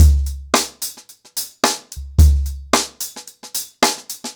HarlemBrother-110BPM.13.wav